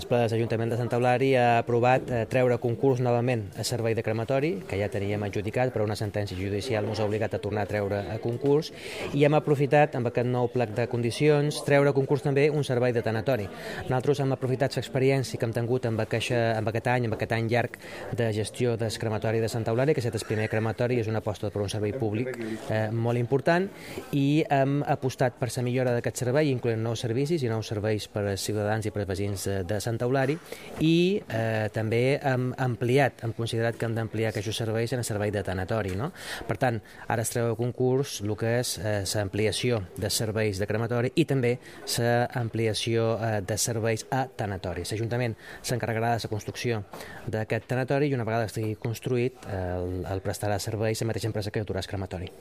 Concejal de Urbanismo Mariano Juan - Servicios Públicos